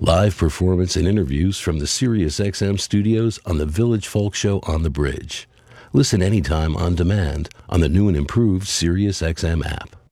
(captured from the web broadcast)
05. advertisement (0:10)